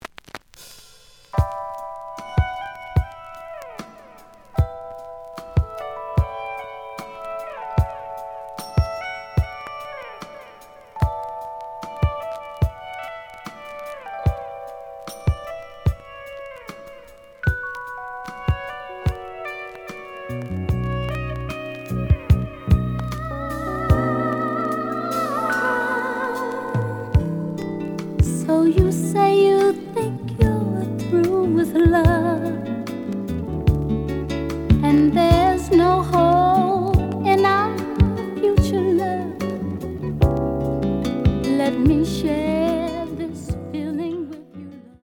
The audio sample is recorded from the actual item.
●Genre: Soul, 80's / 90's Soul
Looks good, but slight noise on both sides.